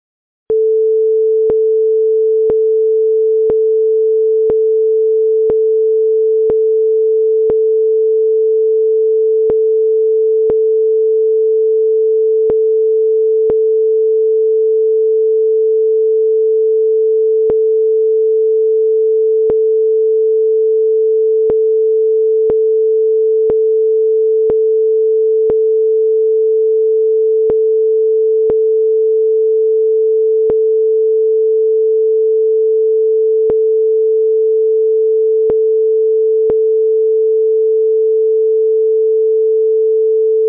PSKを音で試したけど、人がデコードするの難しい（440Hzの音を干渉させてみてください）